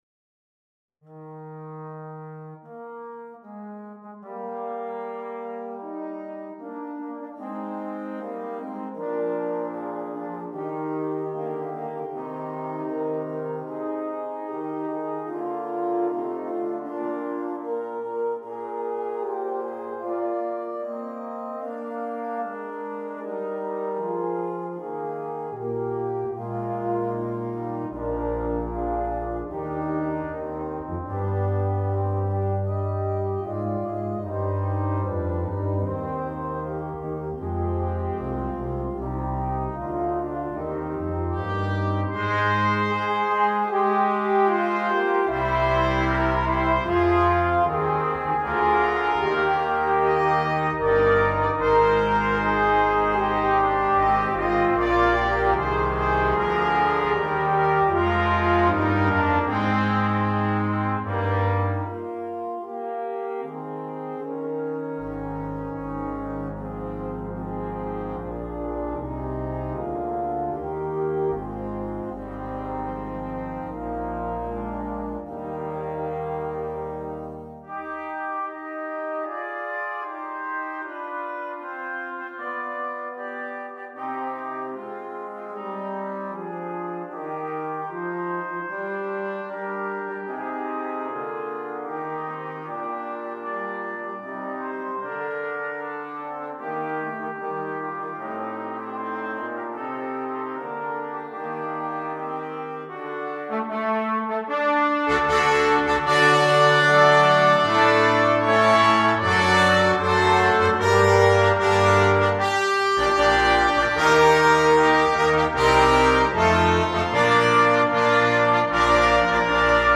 2. Blechbläserensemble
10 Blechbläser
ohne Soloinstrument
Klassik